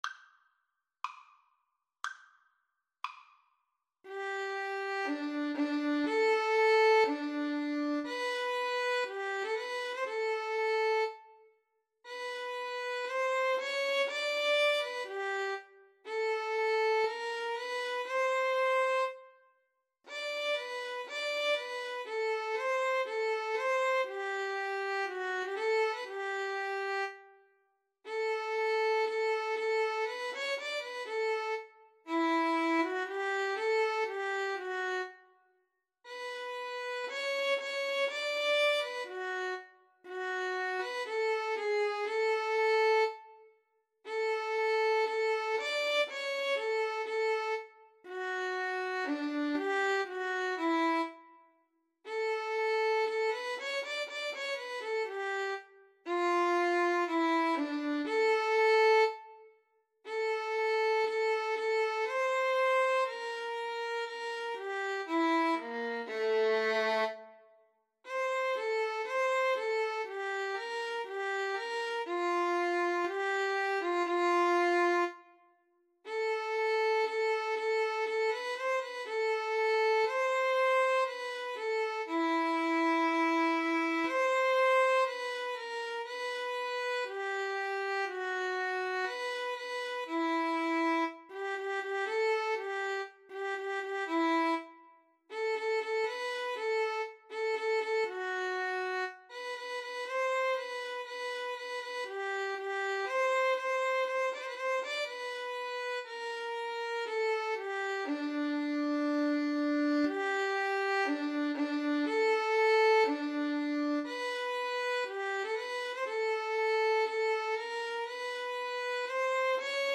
adagio Slow =c.60
Classical (View more Classical Violin-Cello Duet Music)